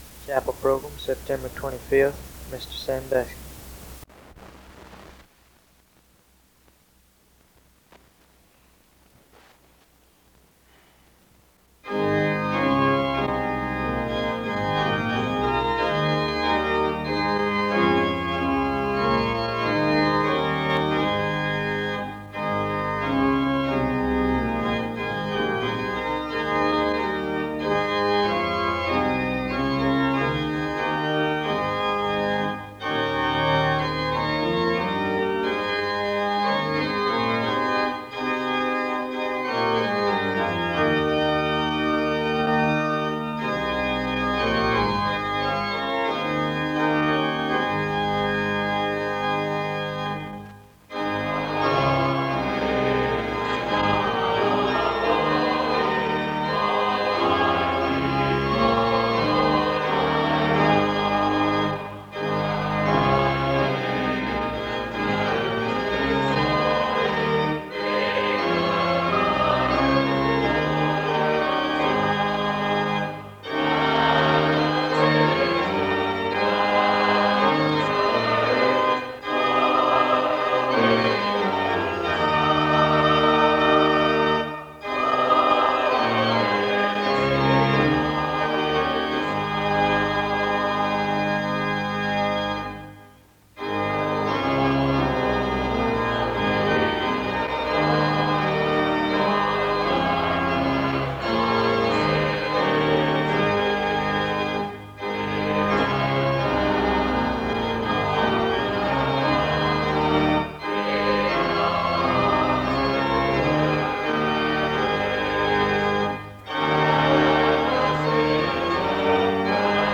The service begins with music from 0:00-3:07. There is a responsive reading from 3:13-4:52. A prayer is offered from 4:55-8:04. Special music plays from 8:15-12:18.
SEBTS Chapel and Special Event Recordings SEBTS Chapel and Special Event Recordings